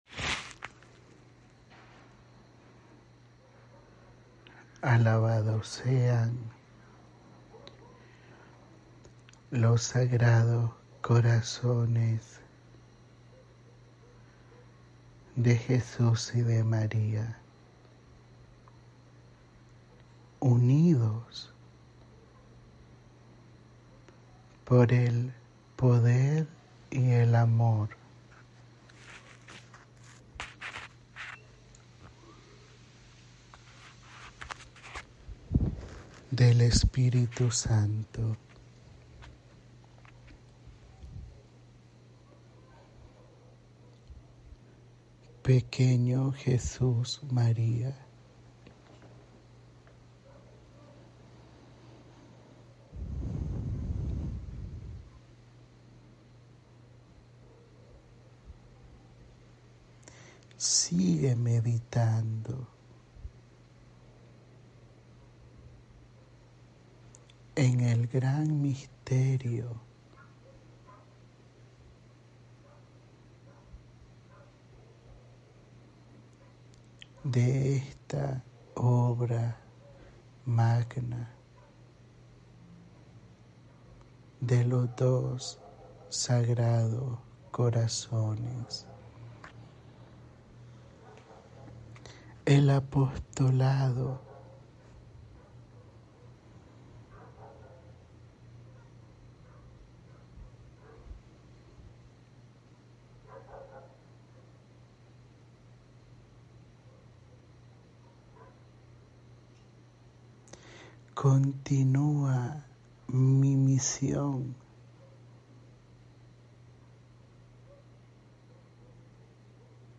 Audio da Mensagem  – voz instrumento: